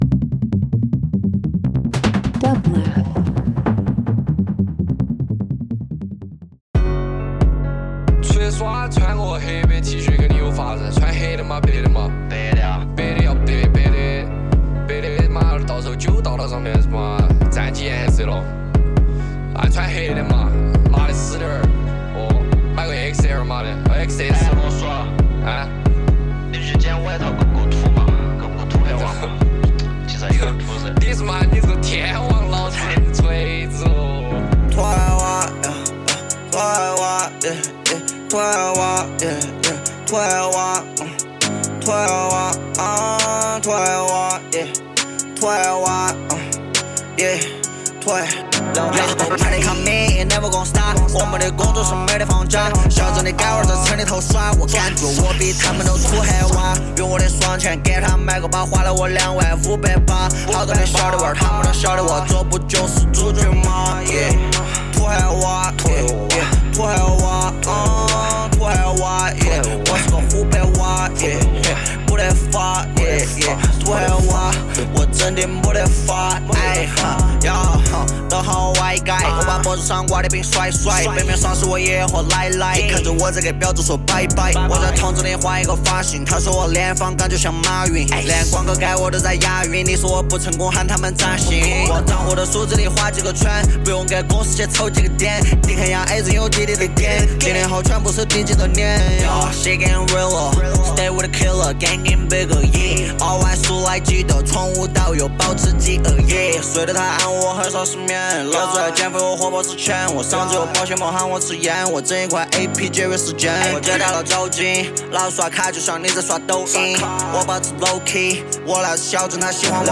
Asian Chinese Hip Hop Rap
Broadcasted on the New Moon.